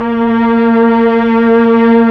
STR_TrnVlnA#3.wav